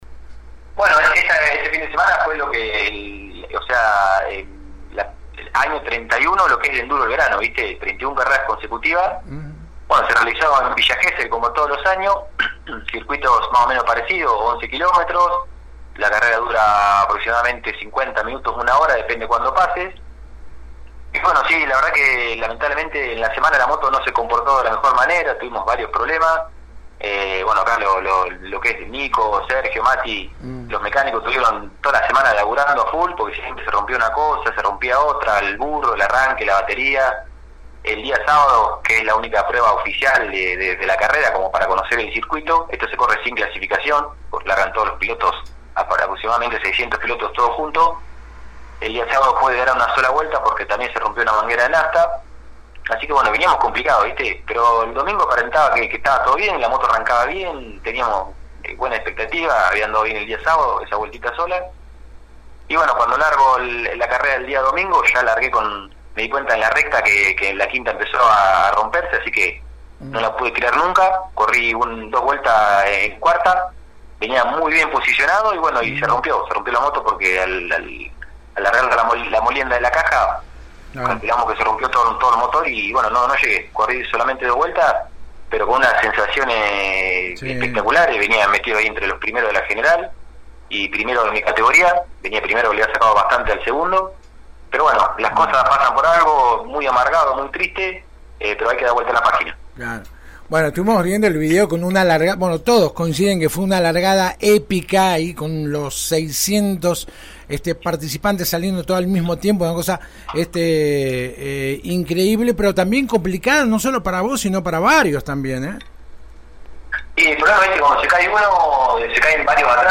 En diálogo con la 91.5